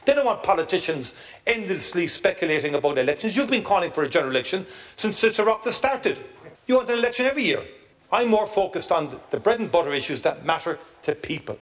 Responding, Michael Martin said the government is committed to seeing out its term, and hit back at Deputy Doherty, saying he doesn’t believe the people share his appetite for a General Election………..